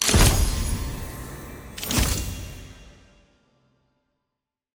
sfx-cs-aram-card-2-appear.ogg